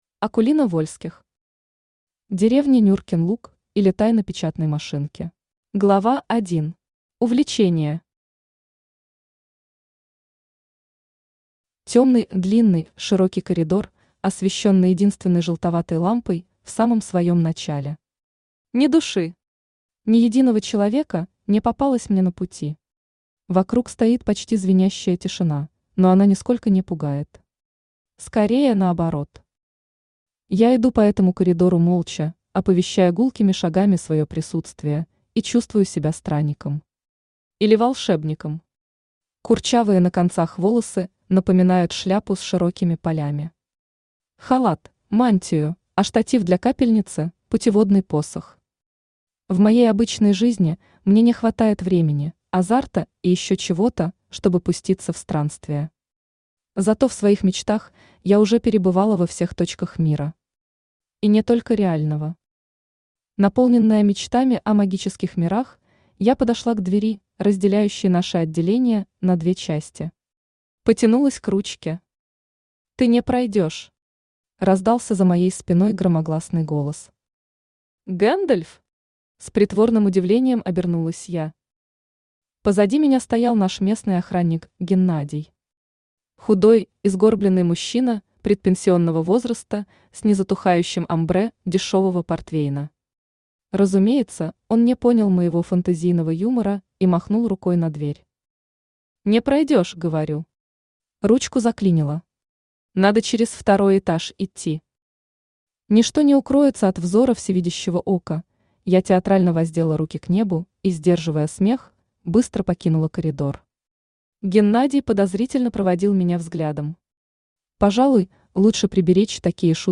Аудиокнига Деревня Нюркин луг, или Тайна печатной машинки | Библиотека аудиокниг
Aудиокнига Деревня Нюркин луг, или Тайна печатной машинки Автор Акулина Вольских Читает аудиокнигу Авточтец ЛитРес.